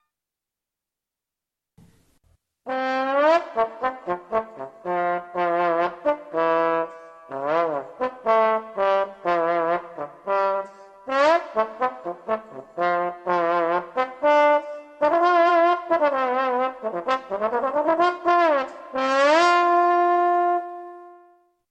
trombon.mp3